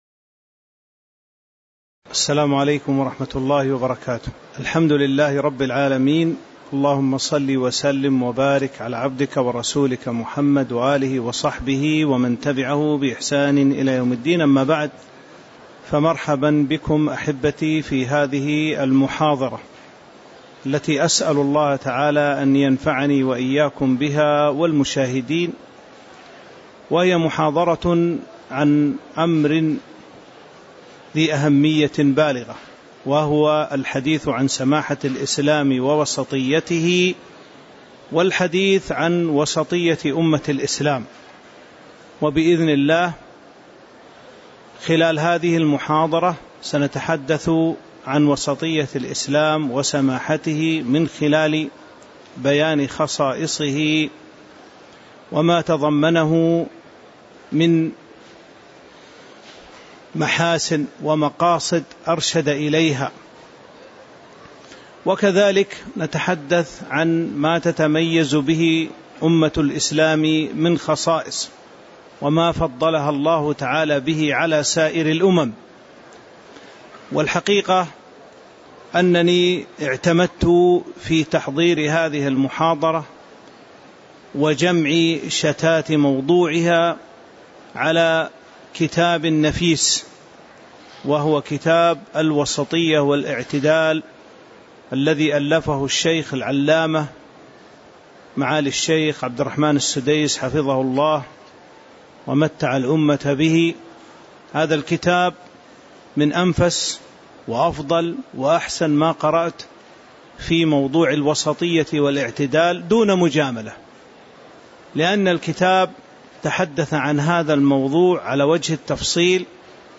تاريخ النشر ١٨ ربيع الثاني ١٤٤٥ هـ المكان: المسجد النبوي الشيخ